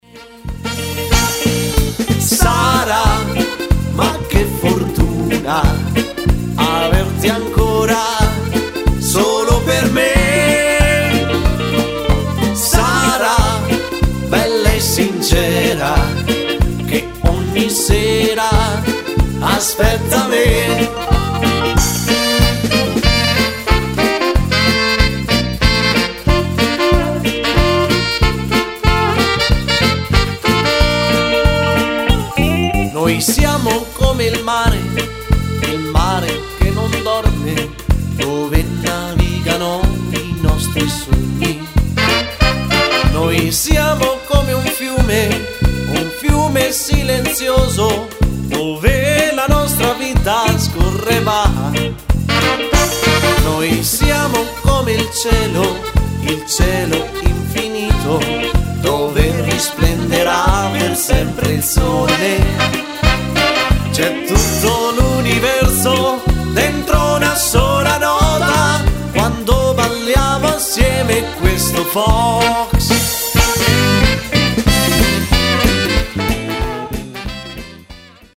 Fox trot
Uomo